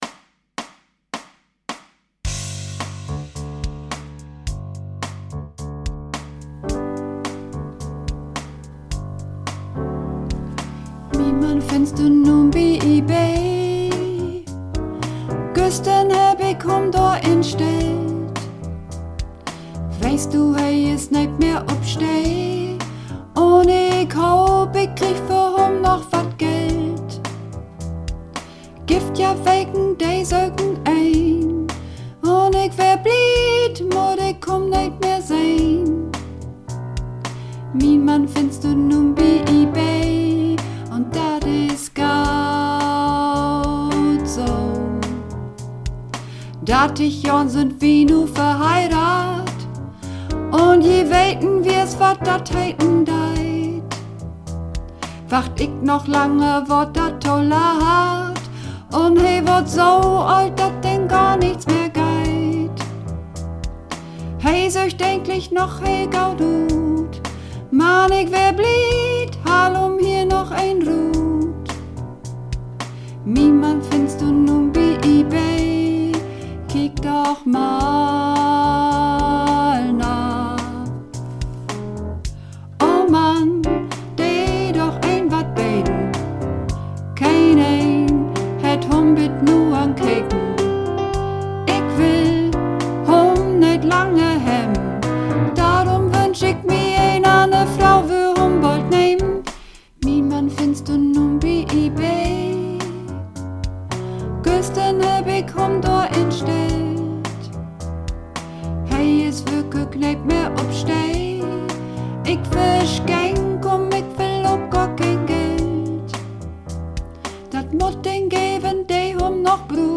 Übungsaufnahmen - Ebay
Ebay (Sopran)
Ebay__3_Sopran.mp3